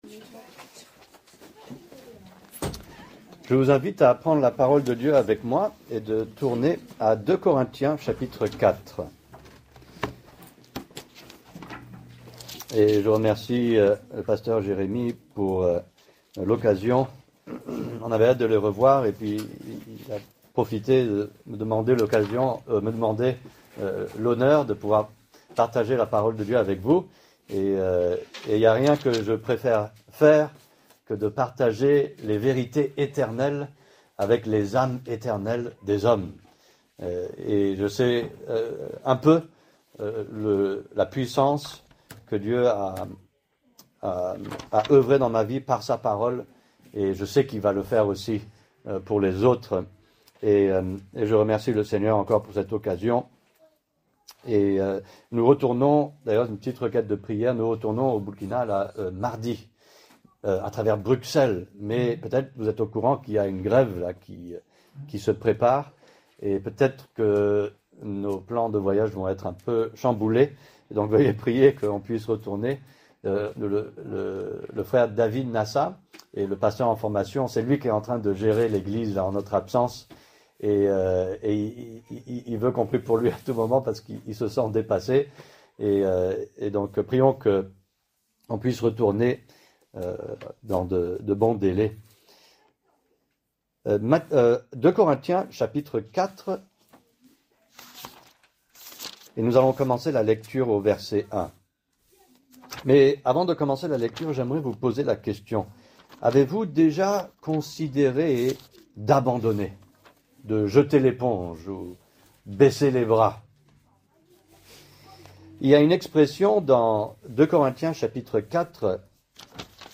Thème: Persévérance Genre: Prédication